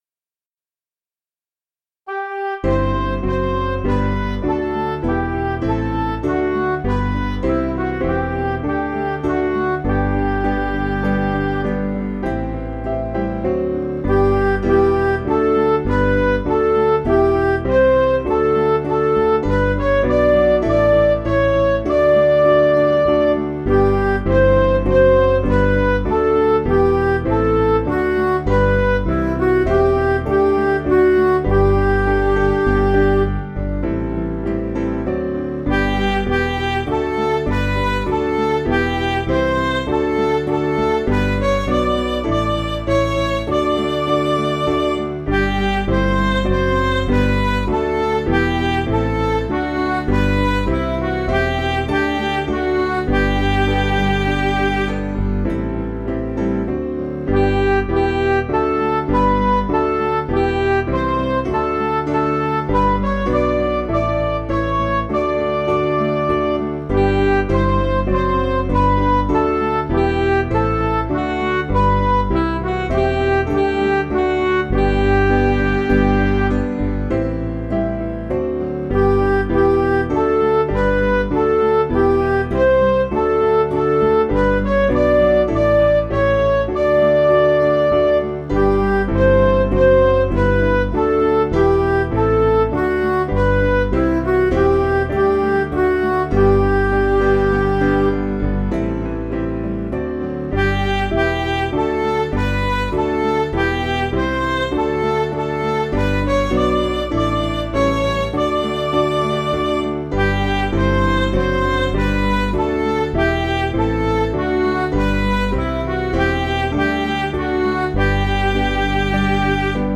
Liturgical Music
Piano & Instrumental
Midi